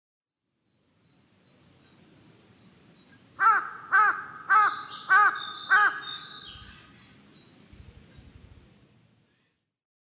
ハシブトガラス　Corvus macrorhynchosカラス科
日光市細尾　alt=1150m
MPEG Audio Layer3 FILE 128K 　0'10''Rec: SONY PCM-D50
Mic: Panasonic WM-61A  Binaural Souce with Dummy Head